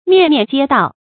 面面皆到 注音： ㄇㄧㄢˋ ㄇㄧㄢˋ ㄐㄧㄝ ㄉㄠˋ 讀音讀法： 意思解釋： 見「面面俱到」。